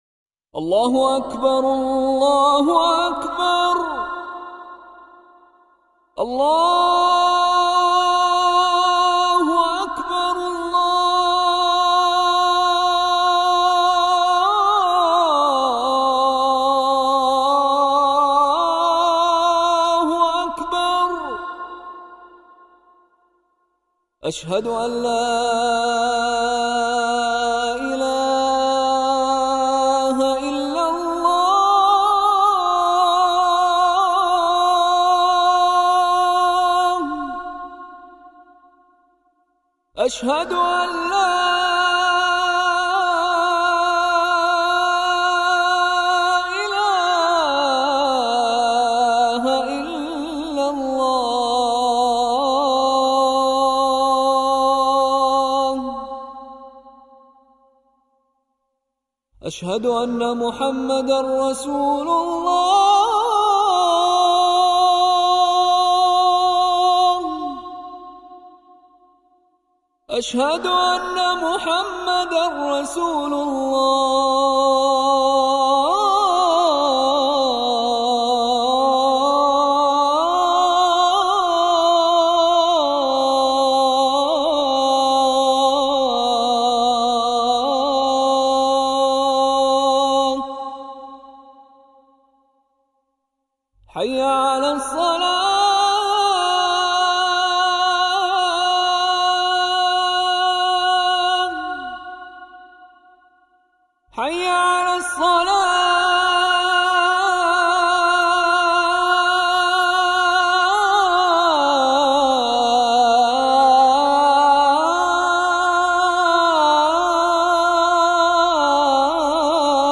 azan_test.wav